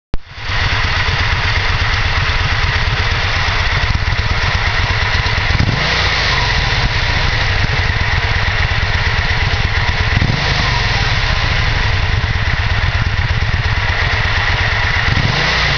Auspuff-Sounds
Original Auspuff